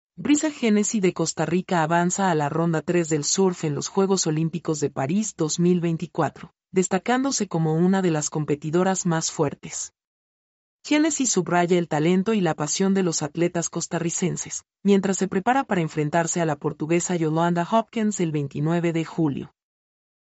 mp3-output-ttsfreedotcom-42-1.mp3